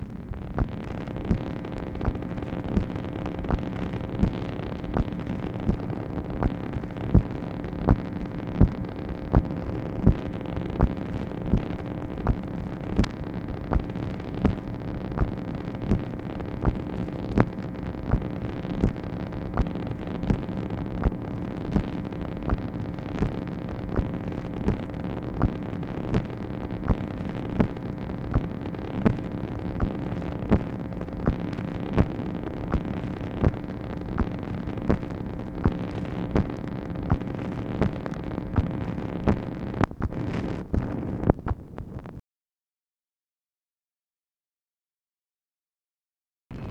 MACHINE NOISE, August 9, 1964
Secret White House Tapes | Lyndon B. Johnson Presidency